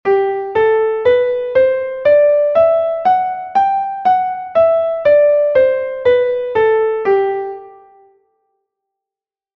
Unha escala é a sucesión de notas ordenadas ascendente e descendentemente.
escala_de_sol1.mp3